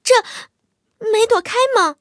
M22蝉小破语音2.OGG